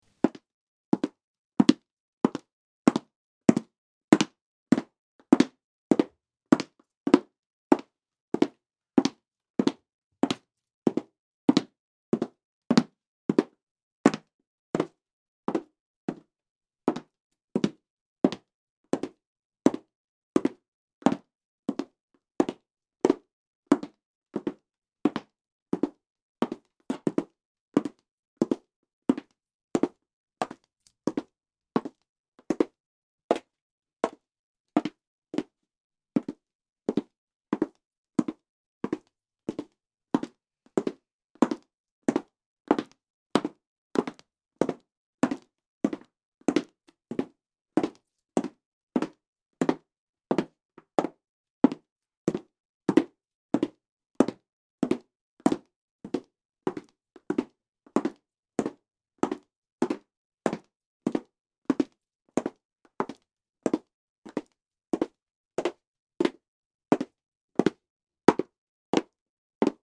Звук каблуков
12. Звук стука высоких каблуков по деревянному полу для монтажа
zvuk-kablukov-po-polu-der.mp3